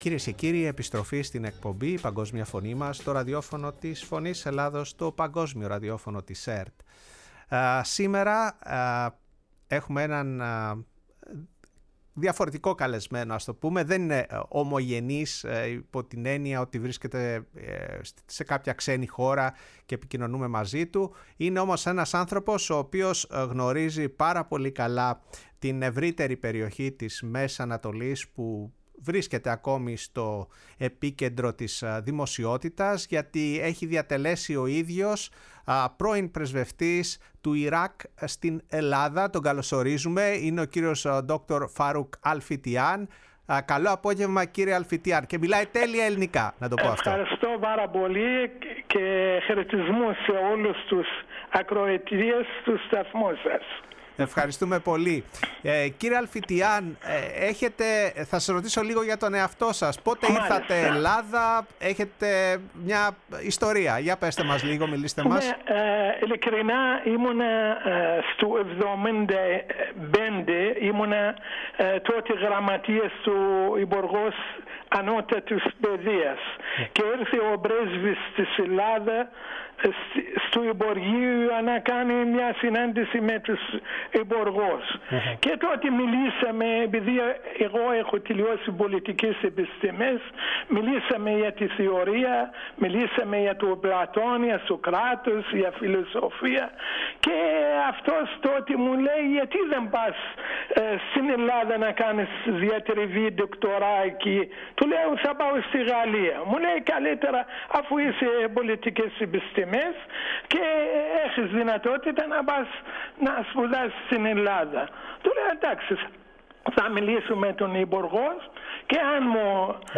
Η ΦΩΝΗ ΤΗΣ ΕΛΛΑΔΑΣ Η Παγκοσμια Φωνη μας ΣΥΝΕΝΤΕΥΞΕΙΣ Συνεντεύξεις ΕΛΛΑΔΑ ΙΡΑΚ ΙΡΑΝ Ισραηλ Συρια ΦΑΡΟΥΚ ΑΛΦΙΤΙΑΝ